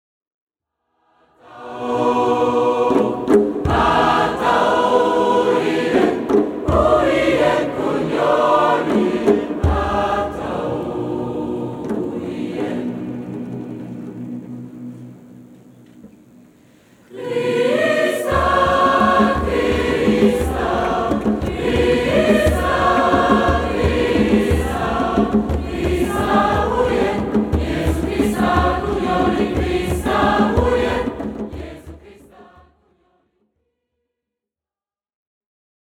en live à la cathédrale de Chartres